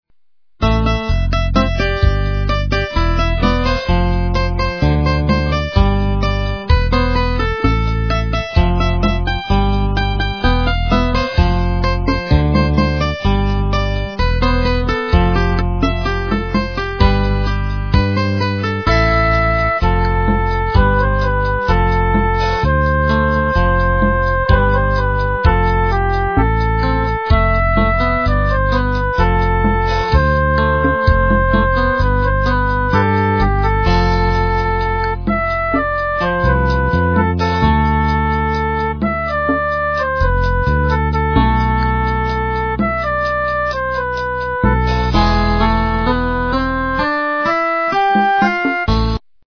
русская эстрада
качество понижено и присутствуют гудки.
полифоническую мелодию